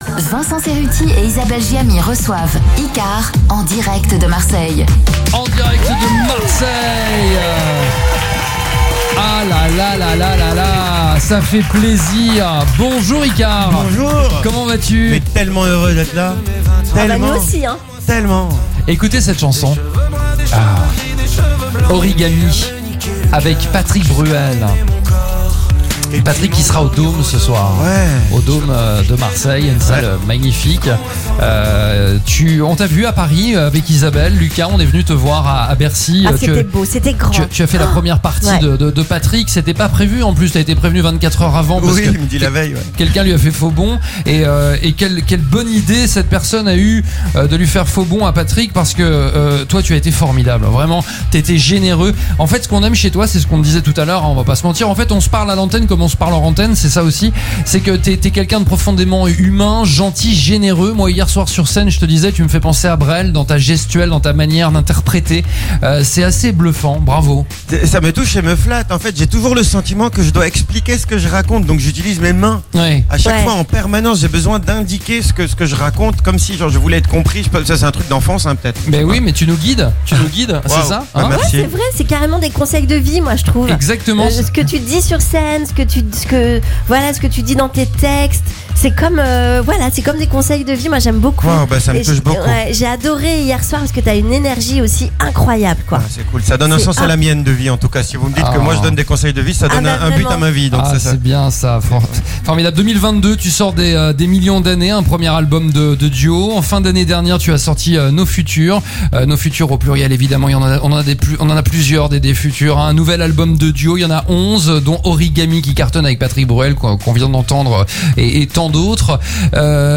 Ycare & Patrick Fiori en Interview